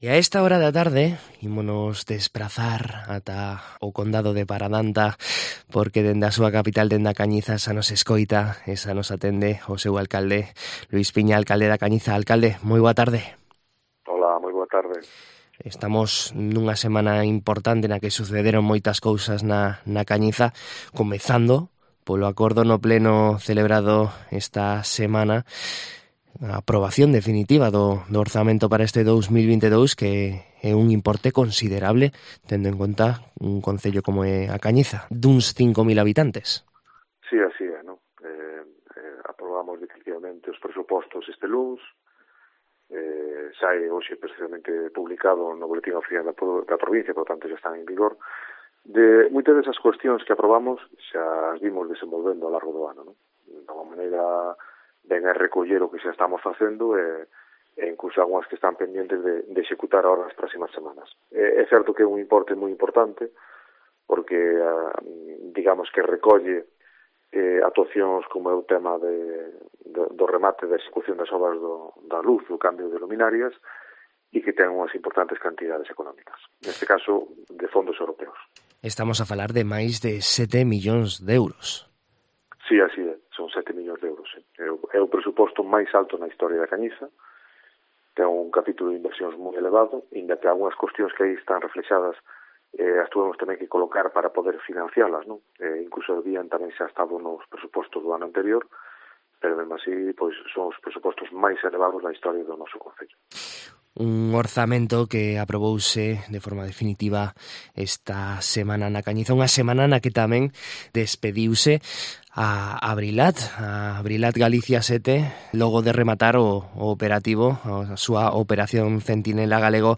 En COPE Vigo coñecemos a actualidade da Cañiza da man do seu alcalde